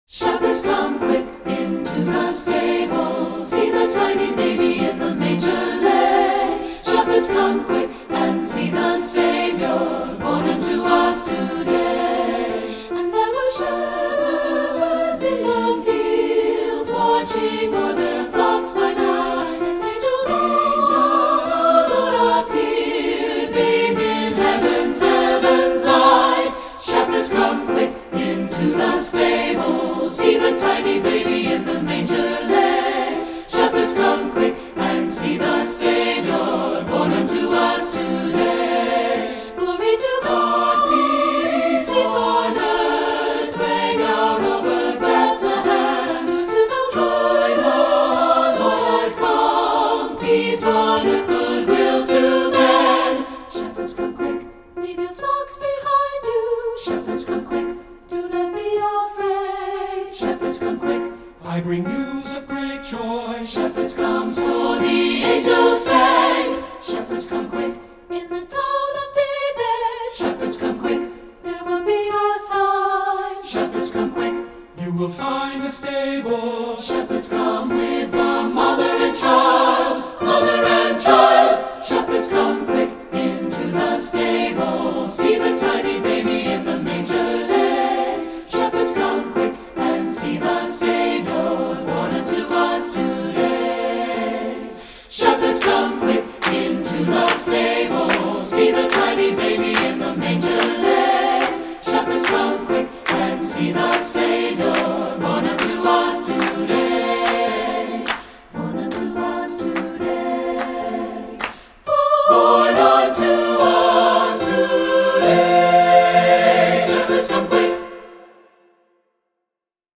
Voicing: 3-Part Mixed a cappella